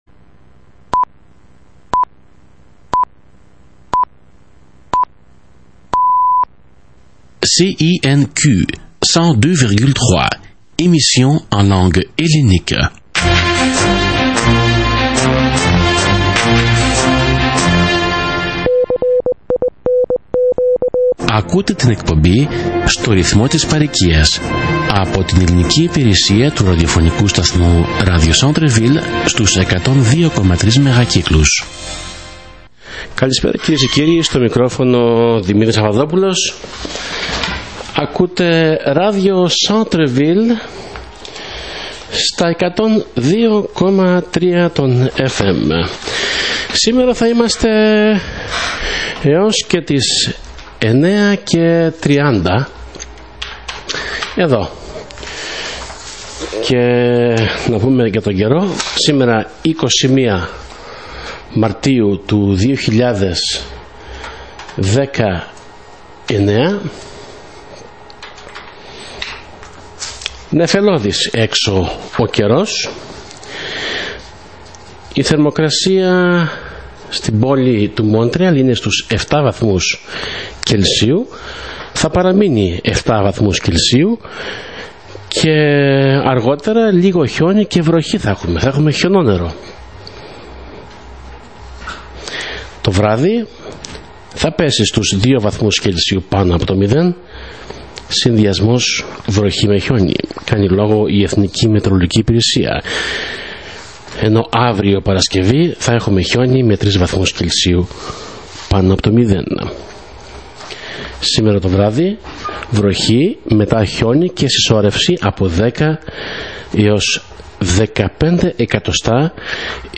[Audio] Ρεπορτάζ από τον 57ο ετήσιο χορό του συλλόγου Κρητών Μόντρεαλ